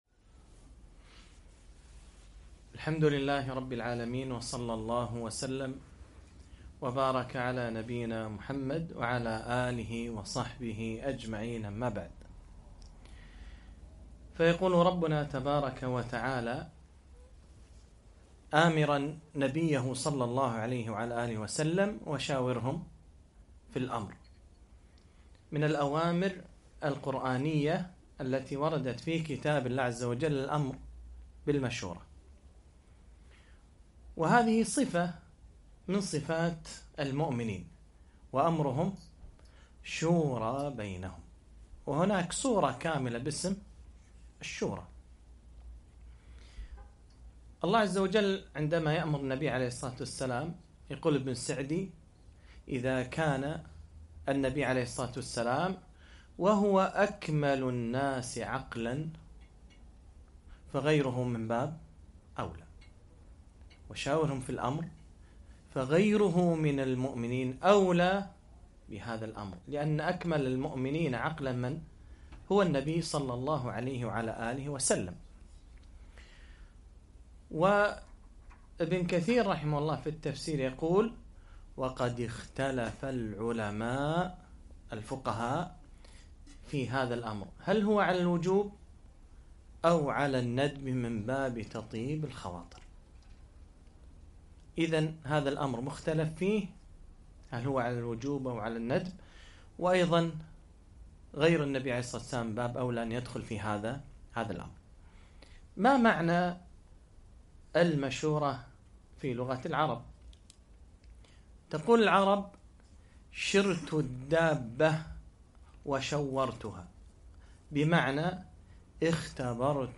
محاضرة - ( وشاورهم في الأمر )